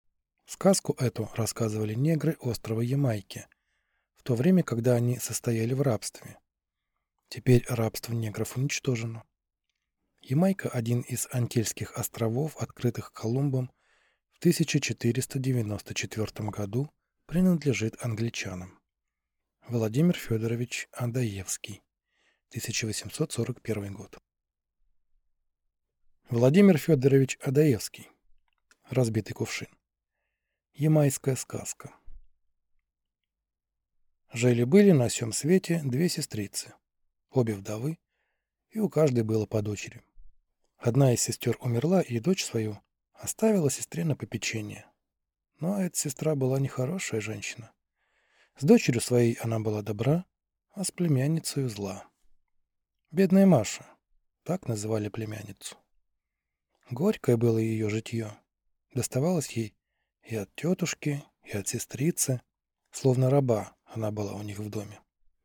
Аудиокнига Разбитый кувшин | Библиотека аудиокниг